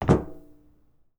grunk / assets / sfx / footsteps / metal / metal5.wav
metal5.wav